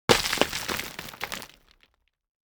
UI_GravelRoll_01.ogg